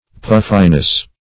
Puffiness \Puff"i*ness\, n.